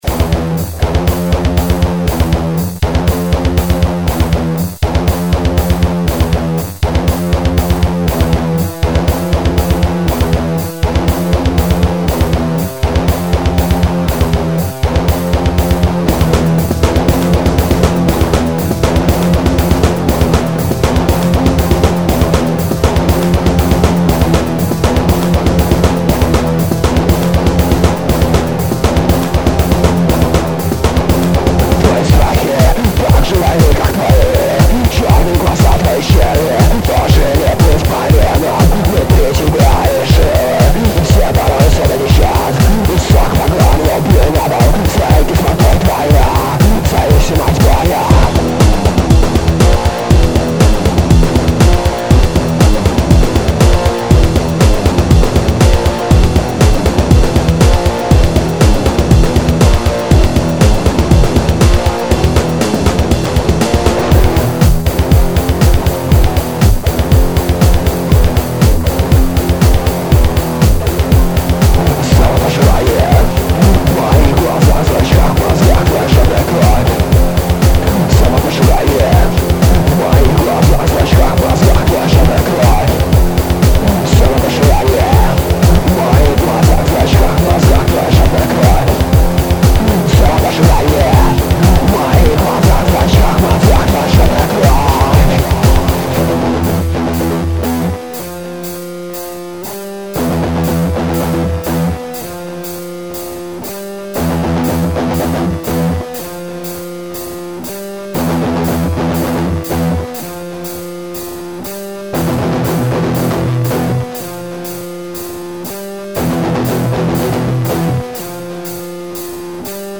Èíäàñòðèàë, Àëüòåðíàòèâà, Ýëåêòðîíèêà, Trash, Hardcore.